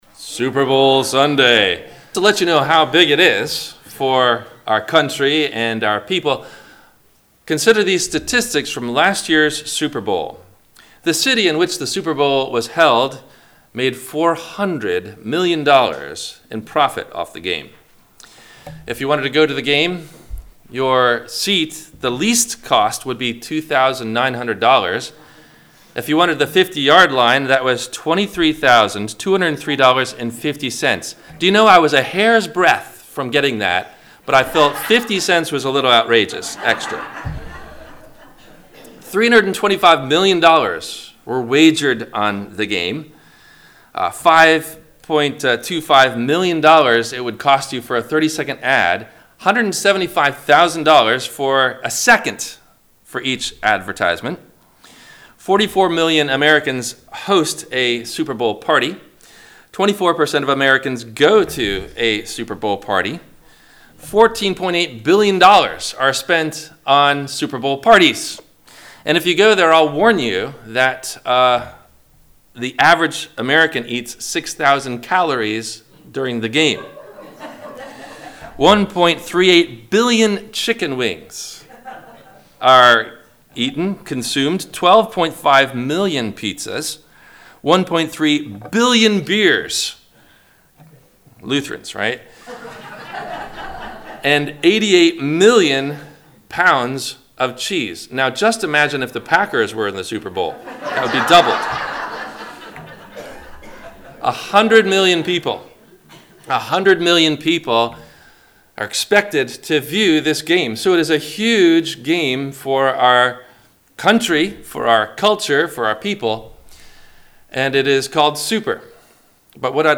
Superbowl VS Super Church – WMIE Radio Sermon – February 10 2020